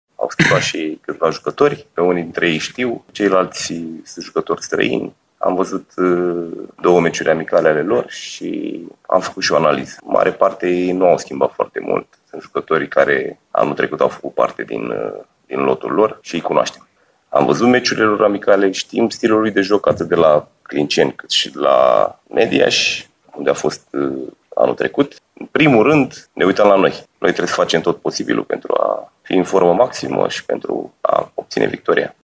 Și de partea cealaltă, tehnicianul Andrei Prepeliță și-a concentrat atenția spre adversar, dar și spre propria formație: